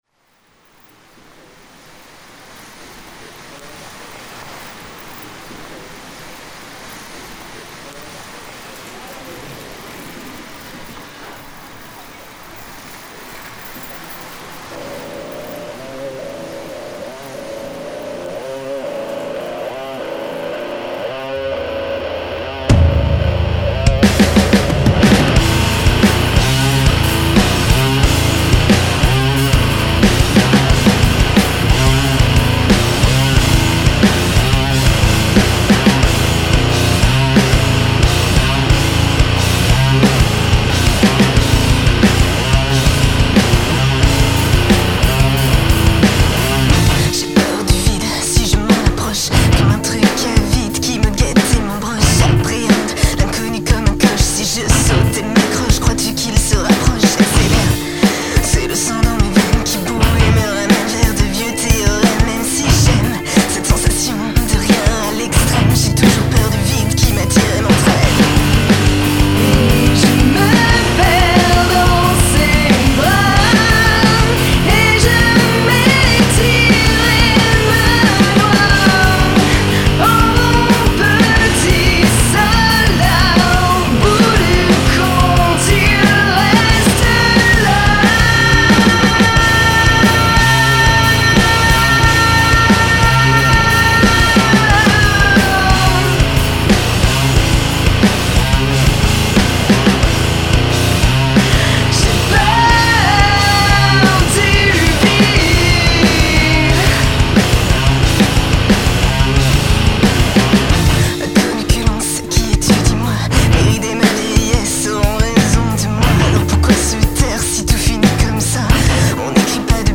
-guitares cool
-kick qui mériterait d'être plus gros (là il est encore trop clicky)
En plus la phase sur les guitares (écoute en mono) est partie grave en sucette. (sur la bande mid des guitares)
Pas de plug d'ouverture de stéréo sur le projet, mais je fais déborder très légérement les grattes sur des bus stéréo, je pense que le pb est la.